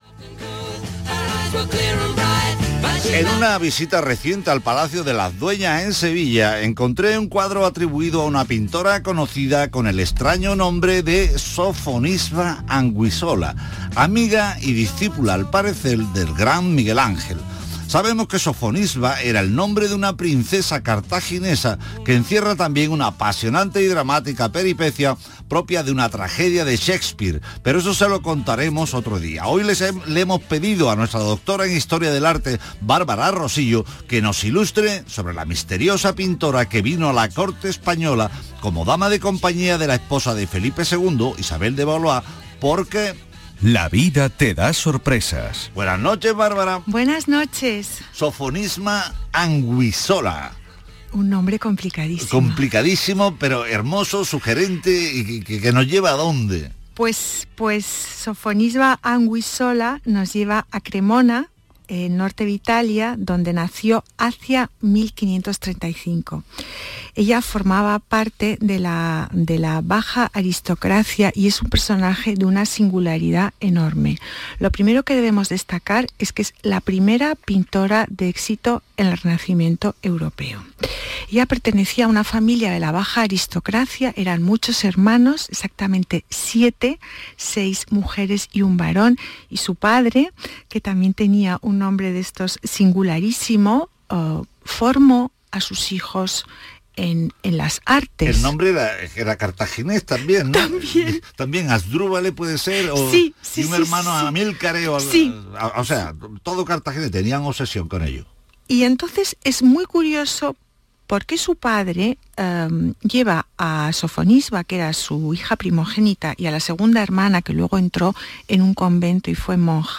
Aquí os dejo mi intervención en el programa de Radio Andalucía Información, «Patrimonio andaluz» del día 06/06/2022.